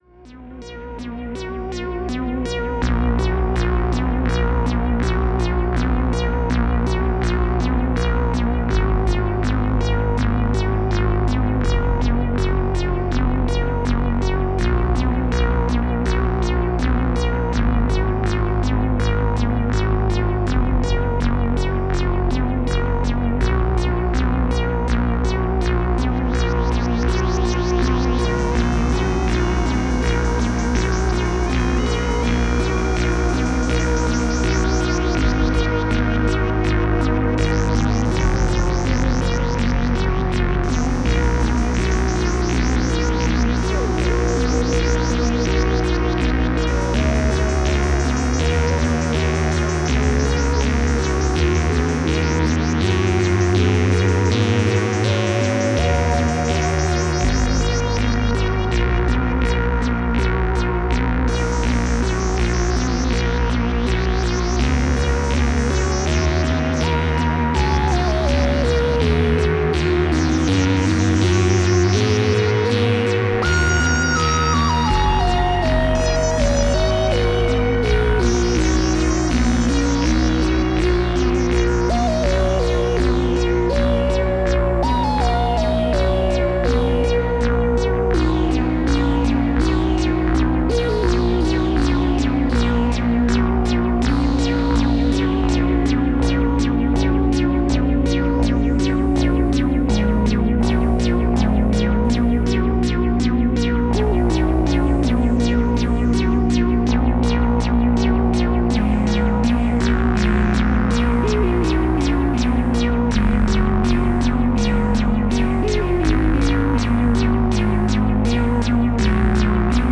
Synthstuff Yamaha CS30
Last month I jammed on this thing for a whole night and came up with these tracks, its all CS30 multitracked with some reverb and delay effects:
a very ‘folky’ sounding synth